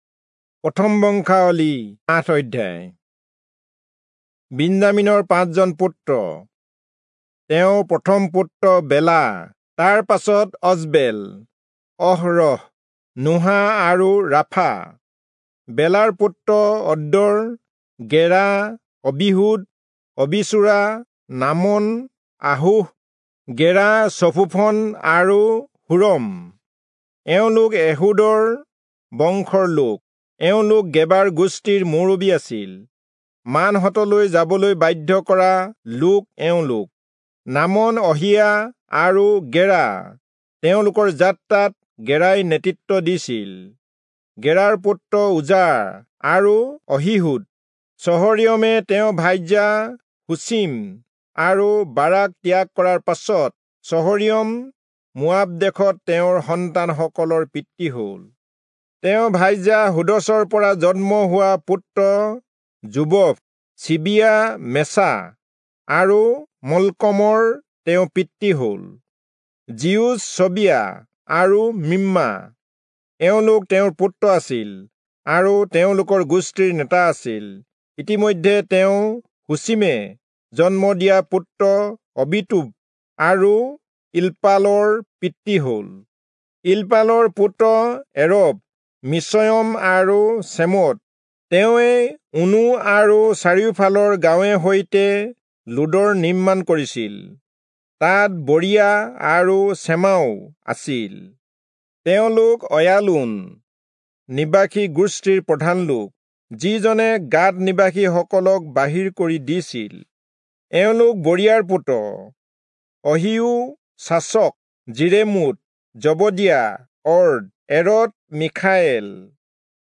Assamese Audio Bible - 1-Chronicles 15 in Mrv bible version